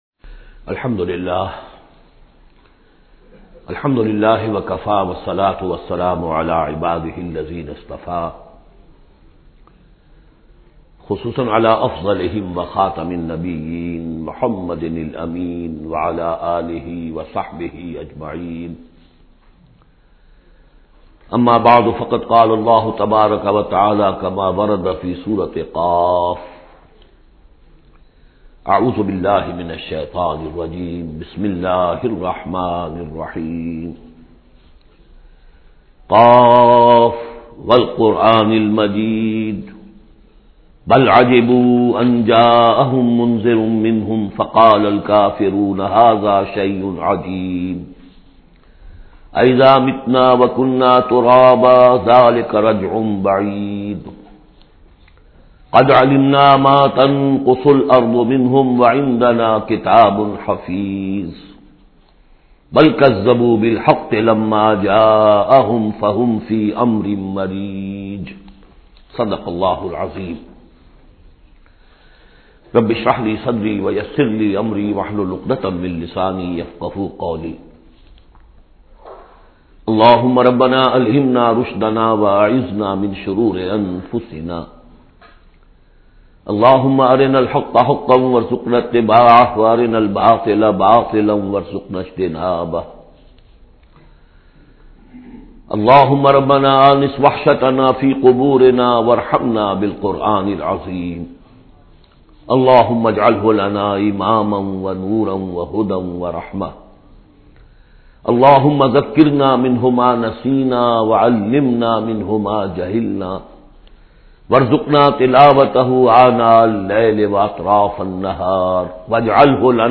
Surah Qaf is 50 chapter of holy Quran. Listen online mp3 tafseer of Surah Qaf in the voice of Dr Israr Ahmed.